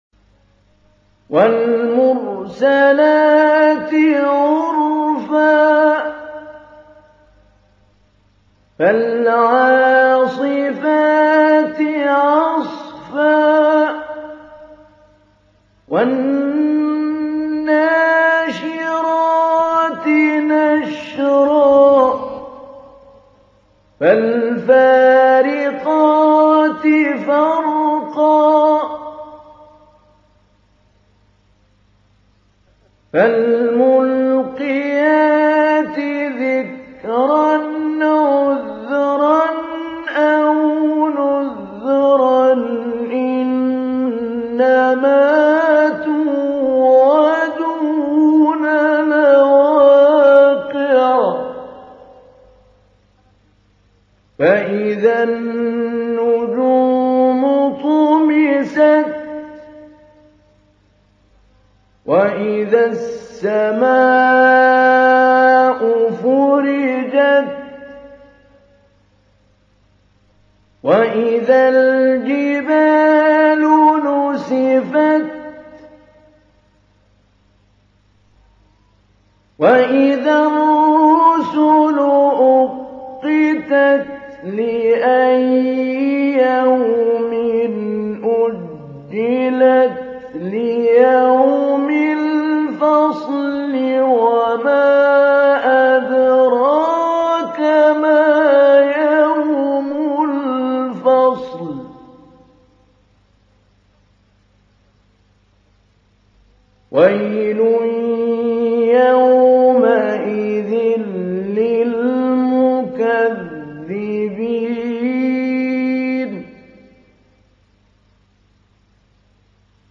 تحميل : 77. سورة المرسلات / القارئ محمود علي البنا / القرآن الكريم / موقع يا حسين